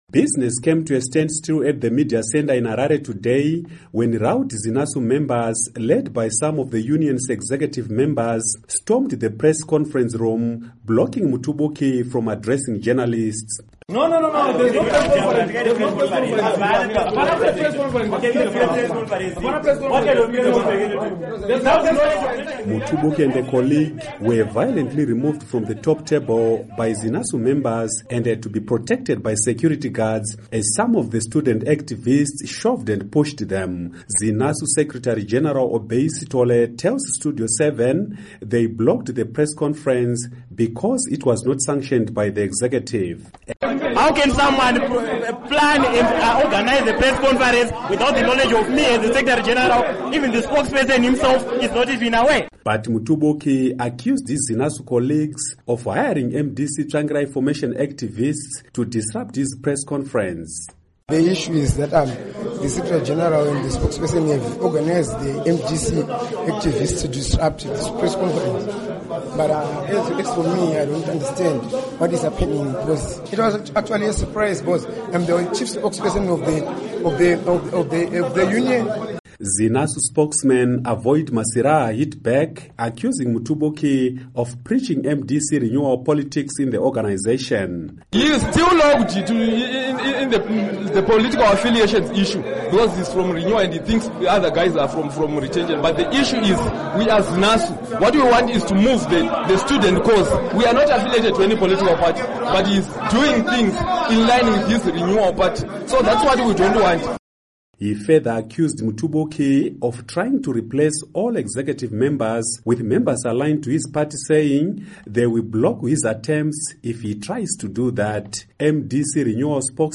Report on ZINASU Clashes